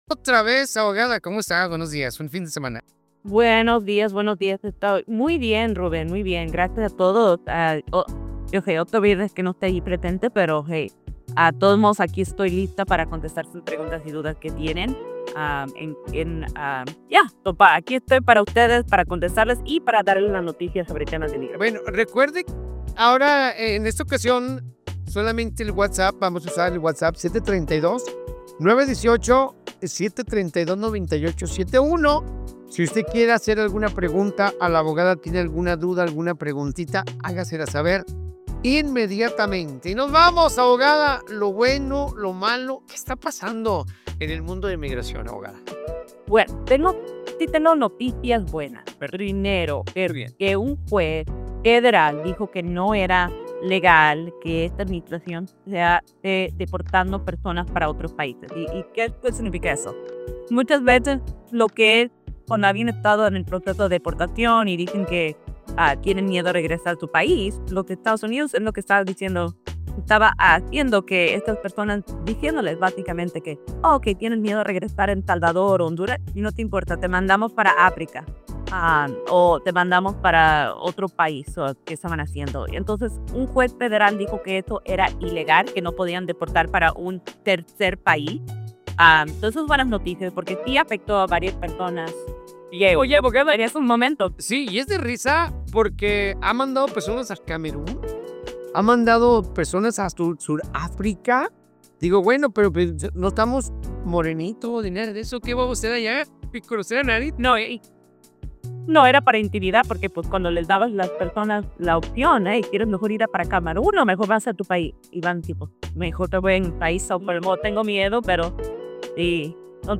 SEGMENTO CON LA ABOGADA